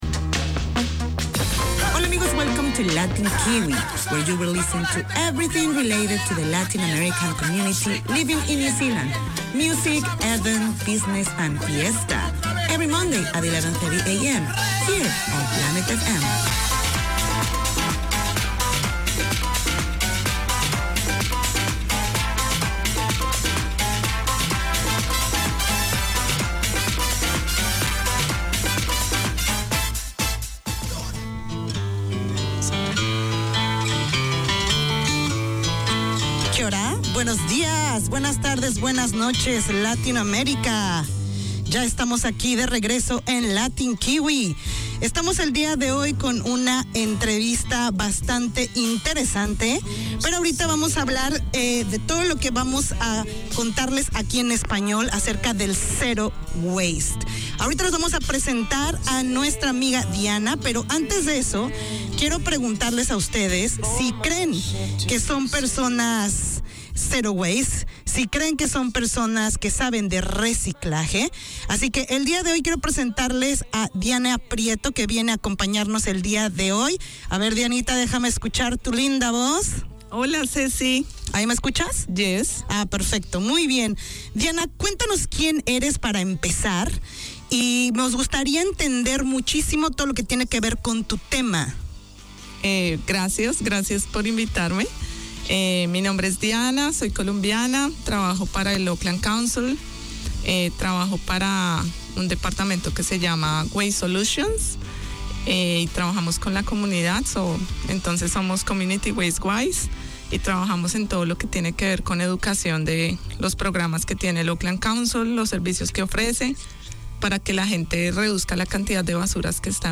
Radio made by over 100 Aucklanders addressing the diverse cultures and interests in 35 languages.
Latin Kiwi 4:25pm WEDNESDAY Community magazine Language: English Spanish Bienvenidos a todos!